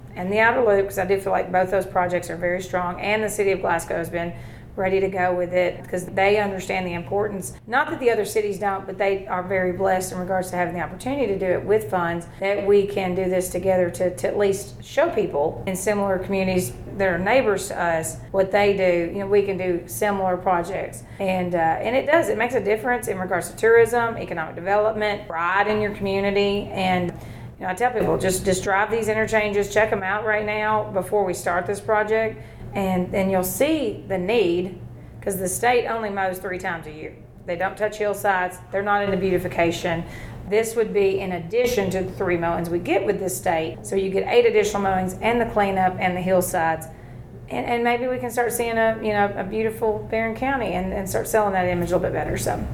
Judge Byrd explains: